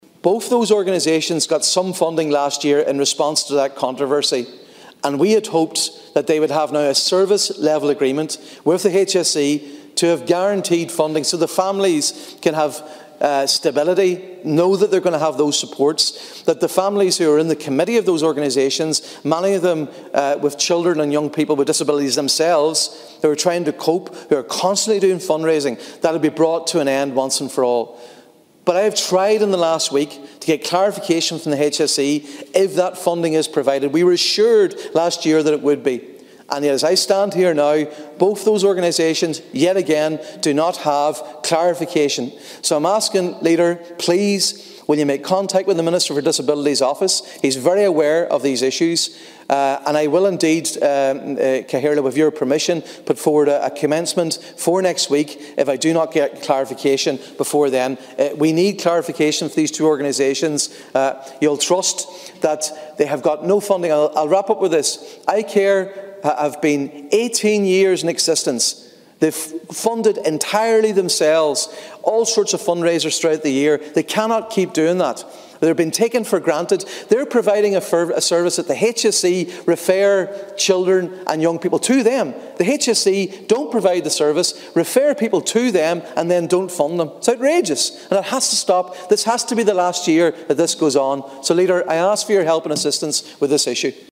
Donegal Senator Padraig Mac Lochlainn says both services relying totally on fundraising initiatives cannot be allowed to continue: